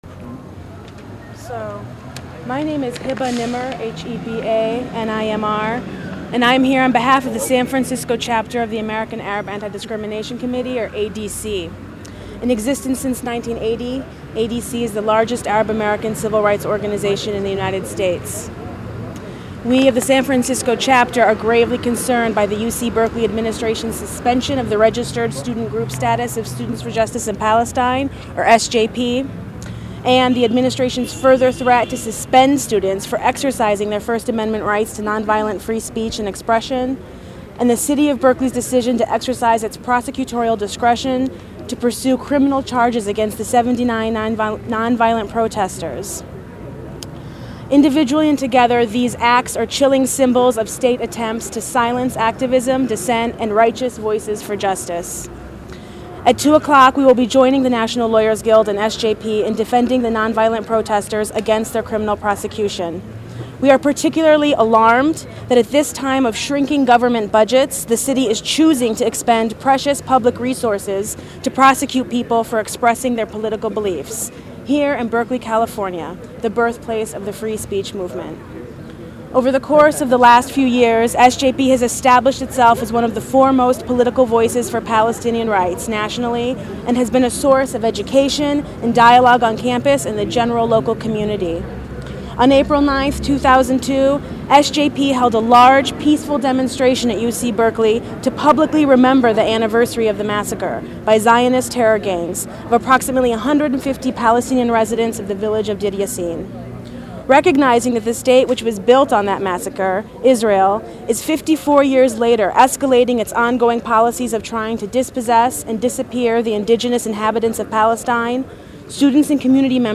Statements from NLG and SJP from a 4/30 press conference held in front of the Berkeley courthouse